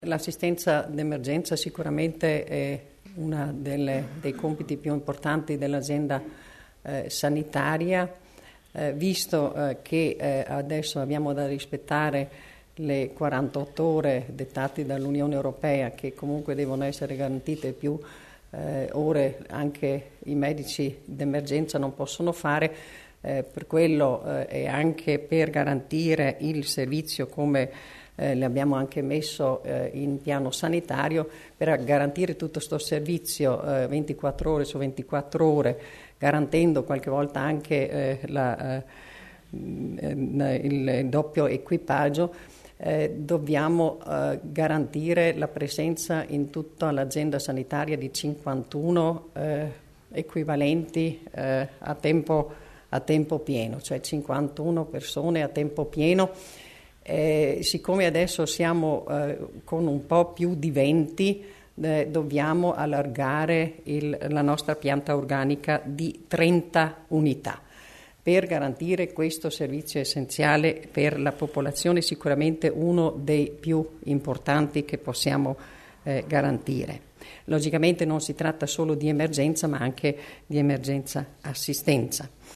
L'Assessore Stocker illustra le novità per il servizio di medicina d'urgenza